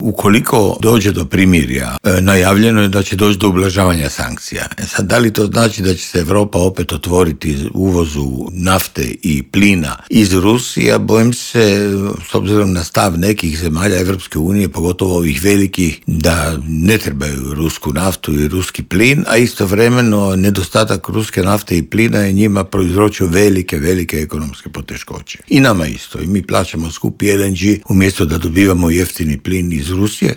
ZAGREB - Dok svijet pozorno prati hoće li doći do mirnog okončanja rata u Ukrajini, energetski stručnjak Davor Štern u Intervjuu Media servisa poručuje da bi u slučaju ublažavanja europskih sankcija trebalo dogovoriti fiksirane cijene nafte i plina.